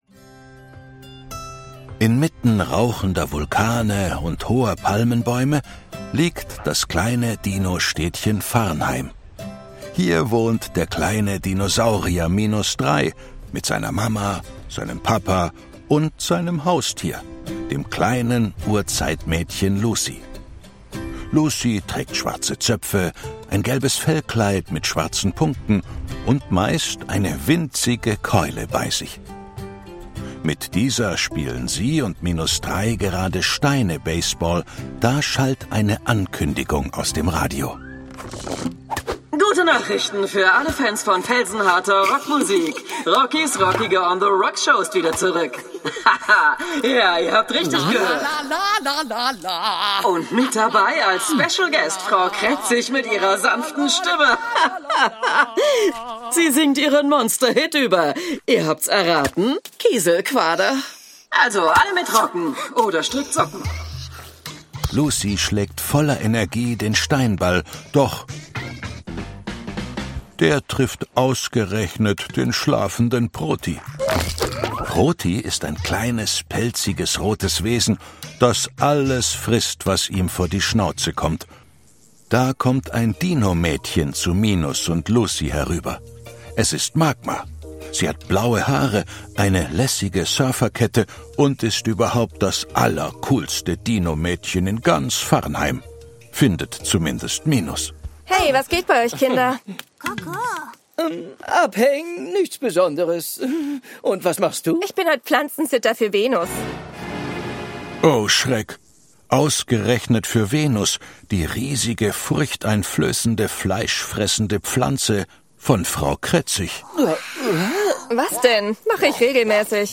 Minus Drei und die wilde Lucy – TV Hörspiel